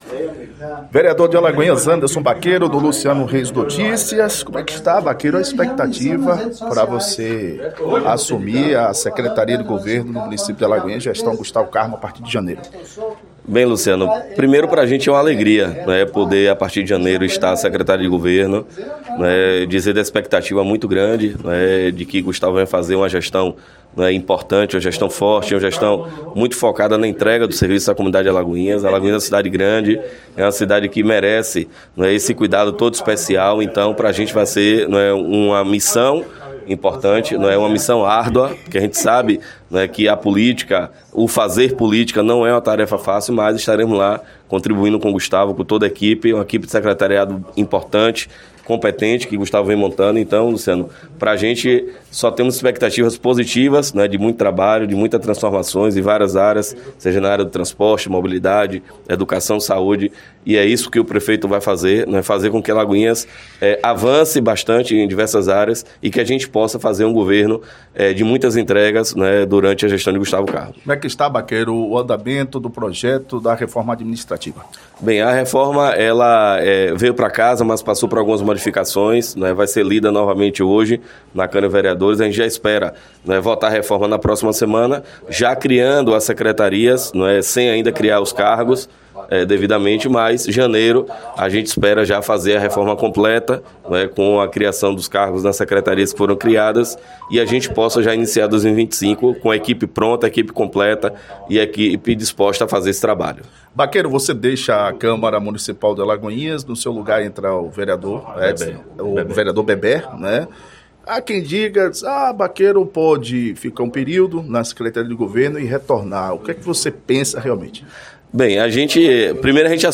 Vereador e futuro secretário de Governo de Alagoinhas, Anderson Baqueiro, durante entrevista na manhã de quinta-feira, 12 de dezembro, no Programa Primeira Mão, pela Rádio Ouro Negro FM 100.5